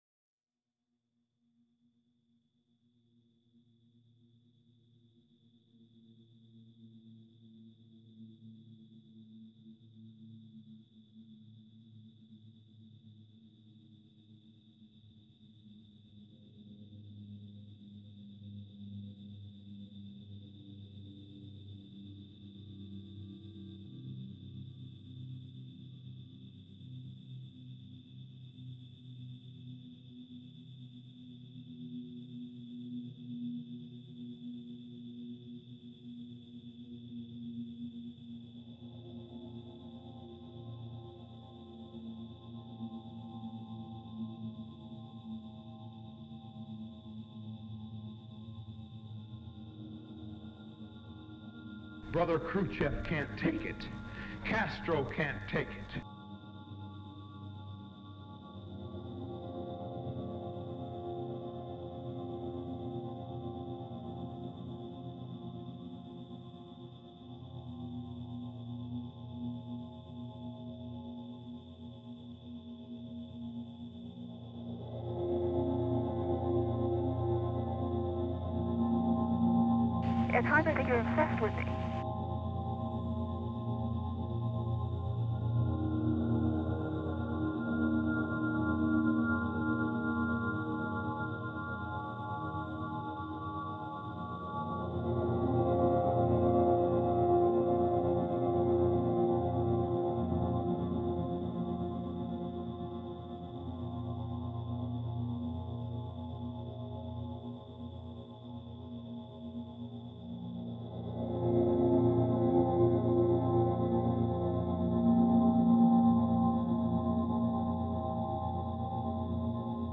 children playing in the background